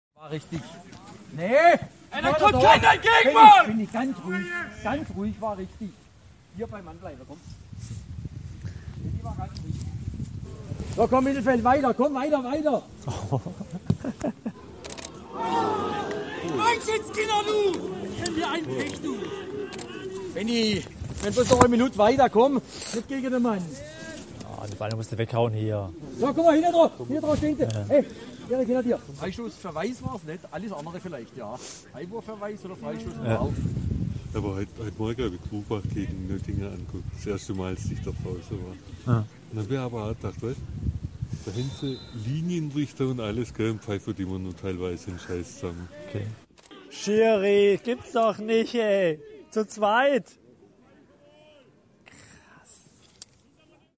Die Zuschauer auf dem Schwarzenberger Sportplatz hatten also schon schmackhaftere Leckerbissen genießen können.